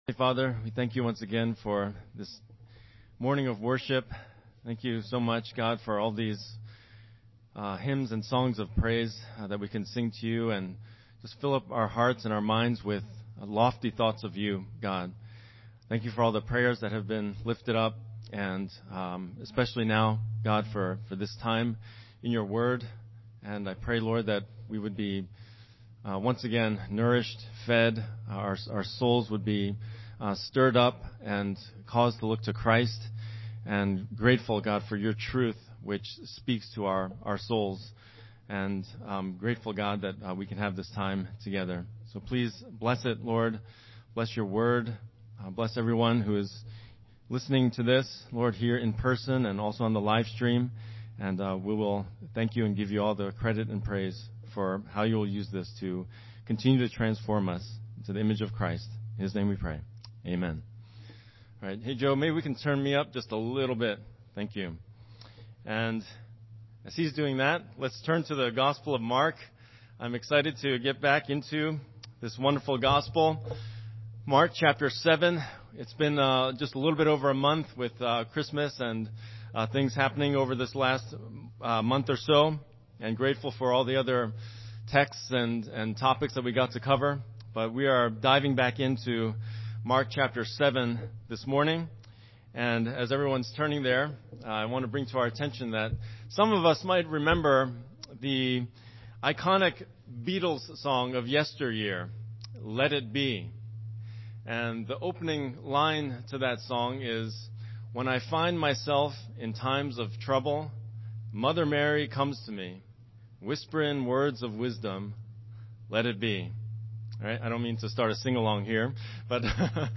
Sermon Theme/Outline: Jesus is the object of faith who draws out the genuineness of faith in all who come to Him in truth.